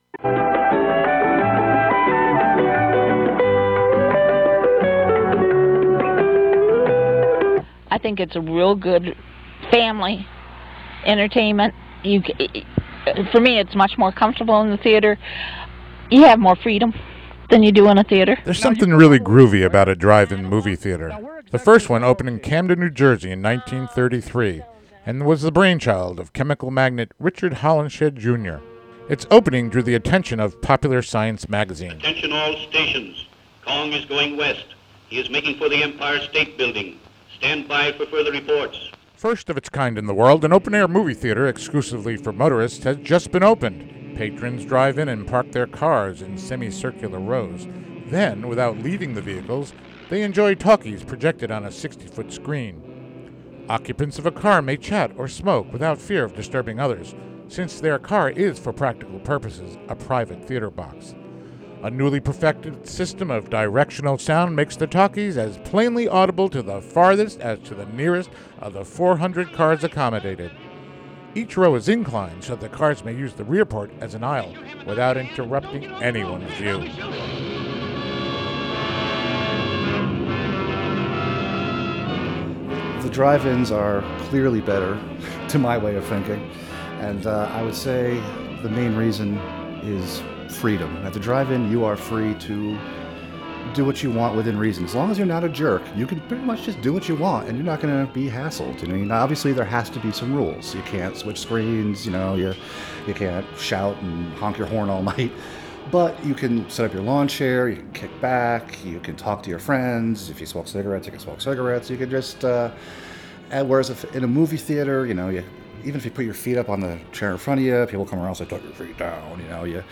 interviews
and vintage drive-in intermission music, trailers, and more.